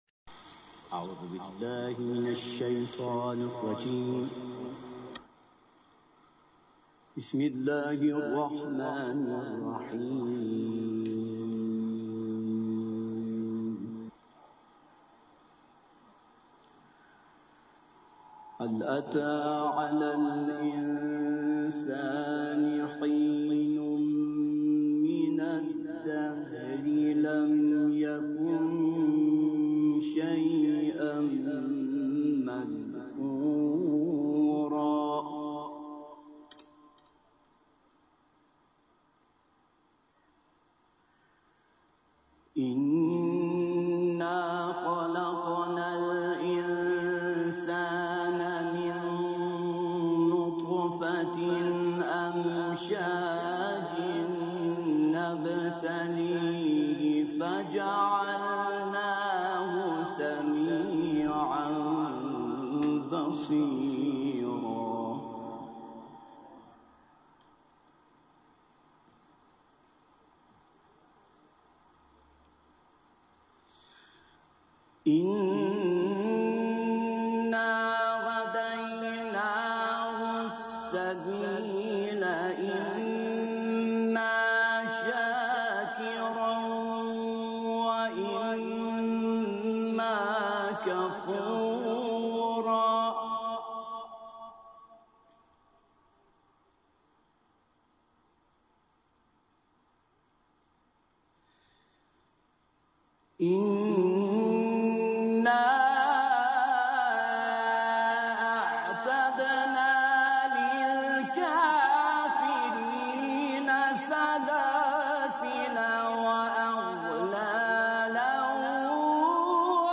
قاری ، صوت تلاوت ، سوره انسان